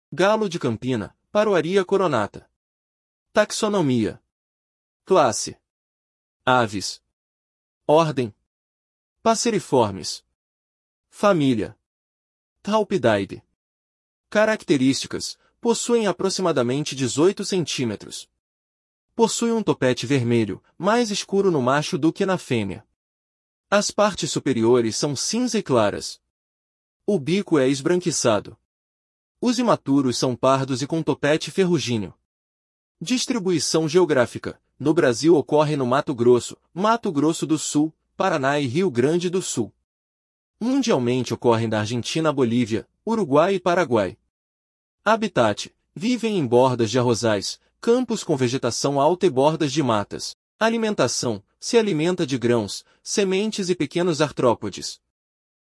Galo-de-campina (Paroaria coronata)